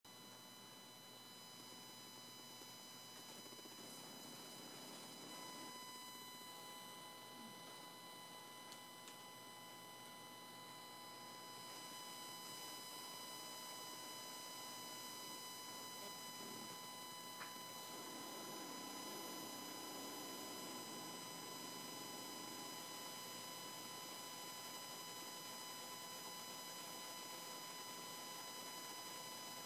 Supposedly in perfect working order, etc. The drivers seem to install perfectly (got the latest one from Tascam's site), but I'm getting a whining noise from my monitors.
Check out the attached file where I recorded the sound with my iphone held up to the monitor speaker. It starts out with no programs running. Around :06, I open Reaper, and the noise gets louder. Around :19 or so, I open up a Reaper project, and the noise gets louder and nastier (additional static, etc.)